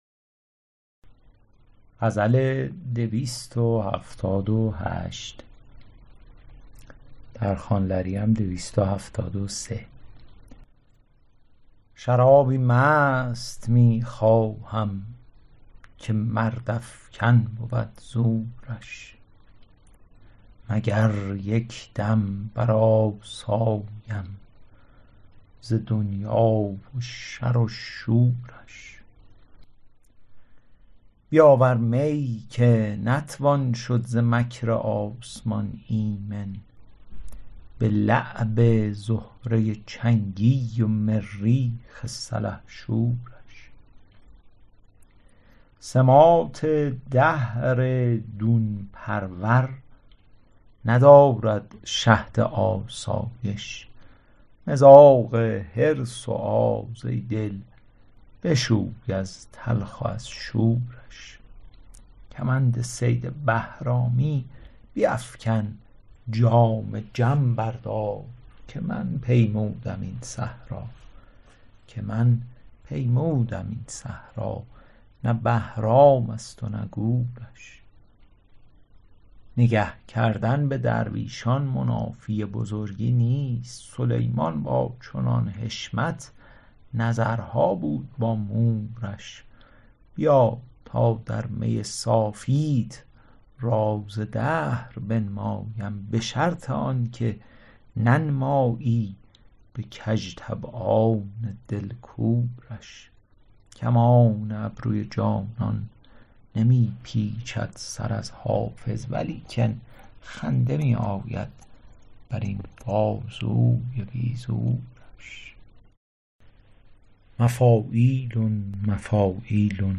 شرح صوتی غزل شمارهٔ ۲۷۸